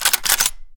sound / sfx / beLoaded